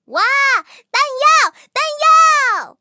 蟋蟀补给语音.OGG